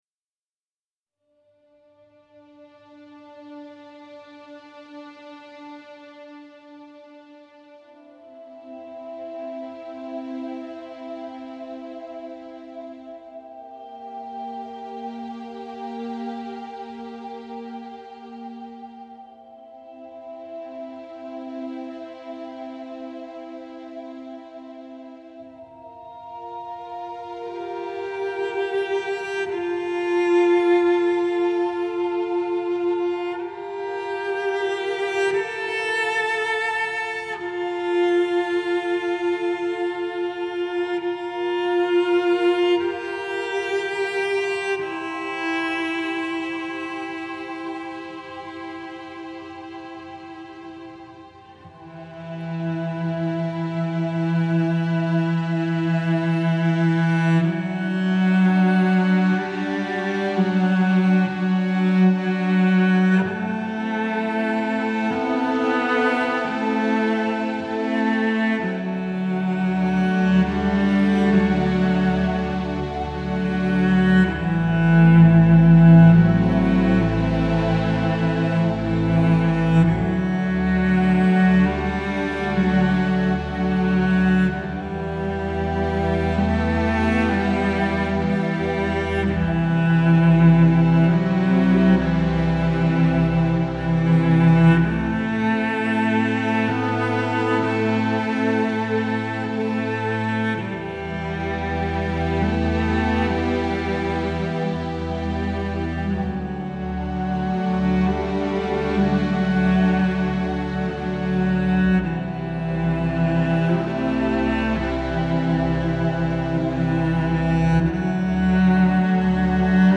موسیقی متن موسیقی بیکلام
موسیقی حماسی